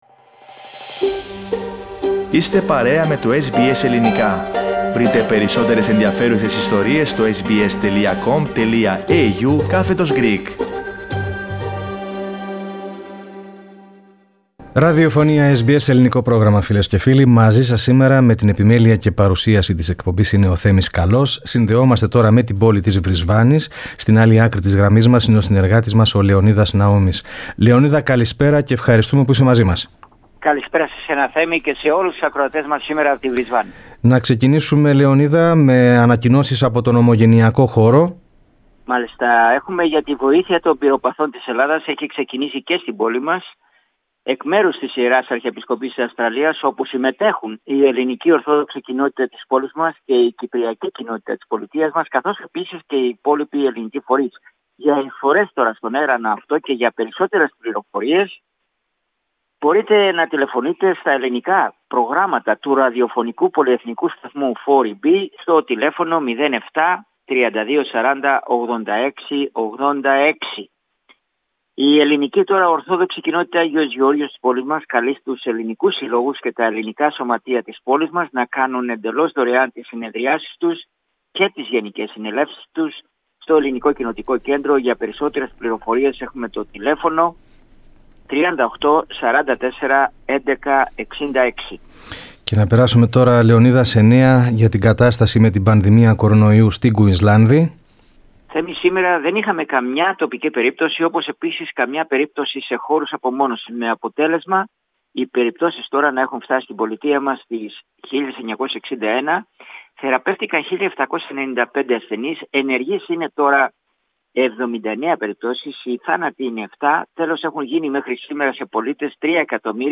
Περισσότερα ακούμε στην ανταπόκριση